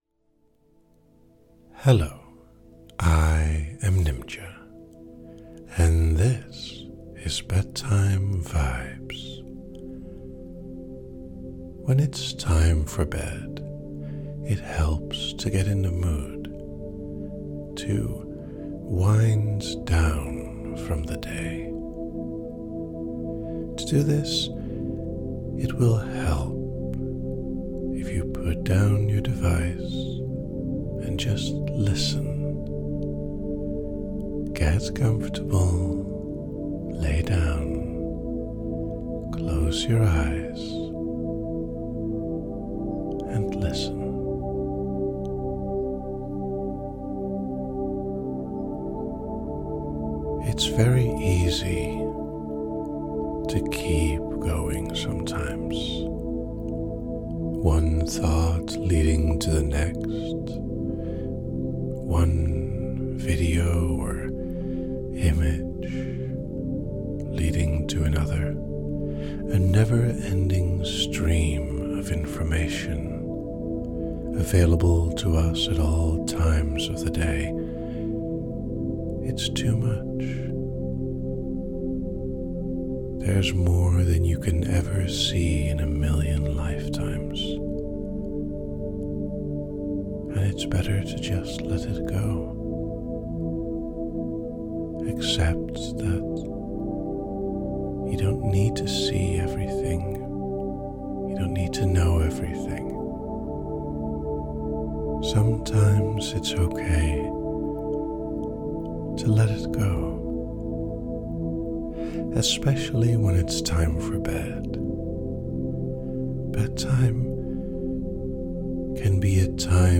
Fast forward --:-- / --:-- Toggle list Toggle shuffle Toggle repeat Toggle fullscreen Detail 1028 - Bedtime Vibes From a simple conversation to some soft whispers to help you get into the right frame of mind for bed. 21:38 - Gentle assurance and guidance towards a calmer state of mind for sleeping.